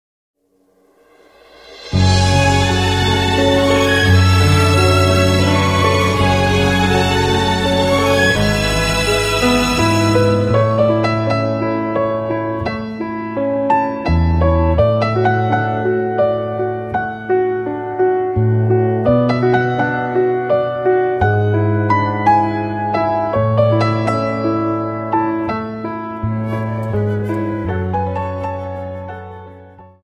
Dance: Slow Waltz